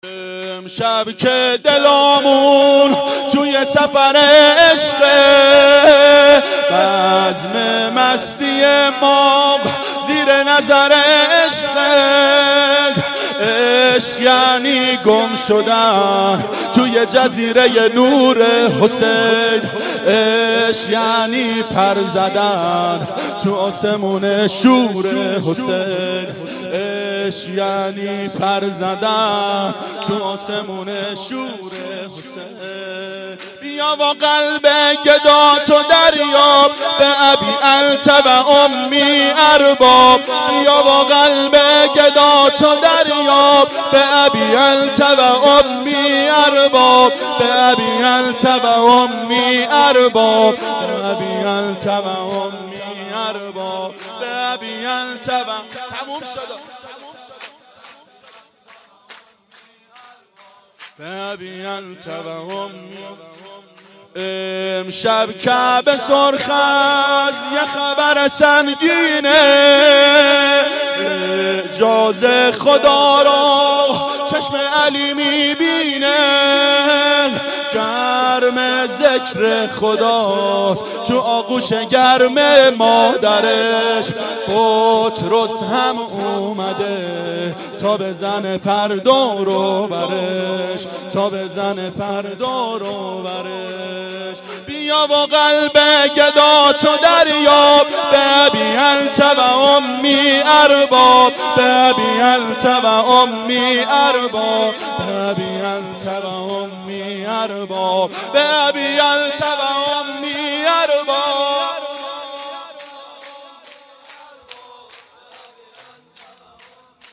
میلاد امام حسین و حضرت اباالفضل علیهماالسلام 93